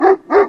wolfgrowl.wav